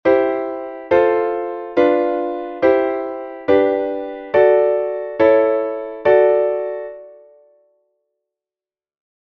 C-D Modulation
Von C-Dur zu D-Dur
Zuerst wird eine Kadenz in C-Dur gespielt, dann folgt der Bindeakkord und sogleich wird die neue Tonart mit einer verkürzten Kadenz gefestigt.
C-D.mp3